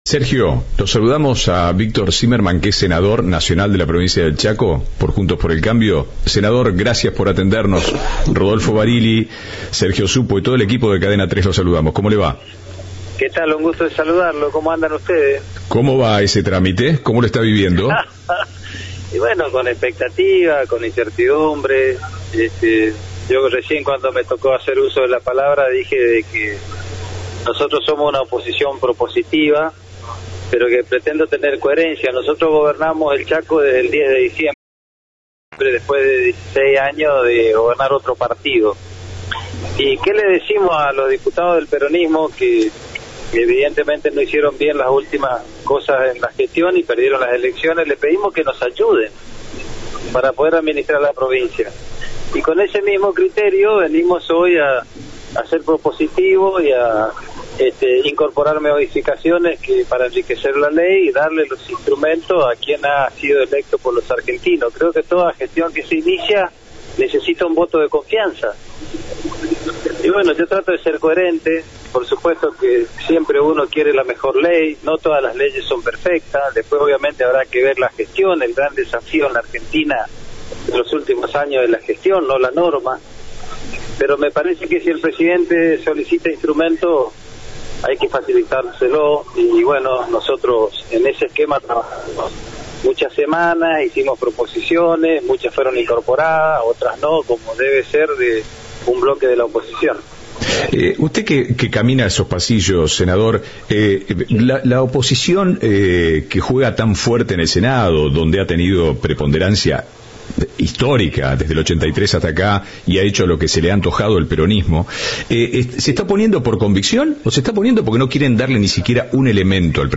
Así lo expresó, en diálogo con Cadena 3, el senador por el Chaco, quien se mostró como una oposición propositiva.